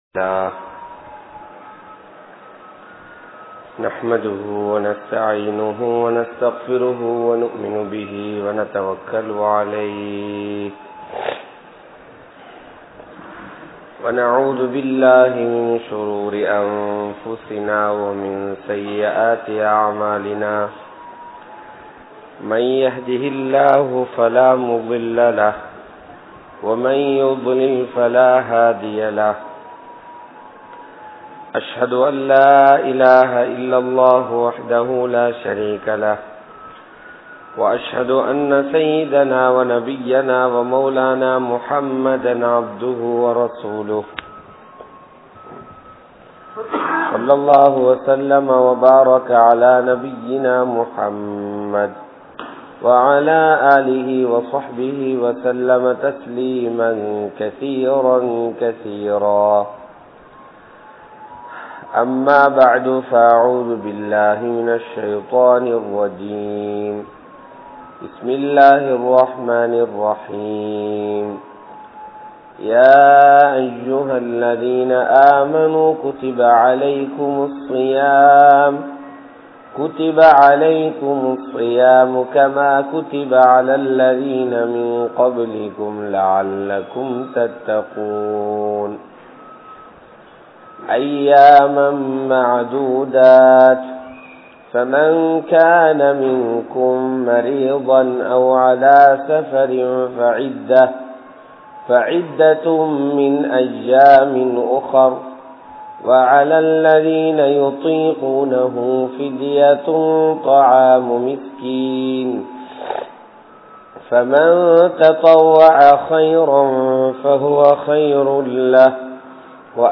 How to Utilize the Holy month of Ramalan | Audio Bayans | All Ceylon Muslim Youth Community | Addalaichenai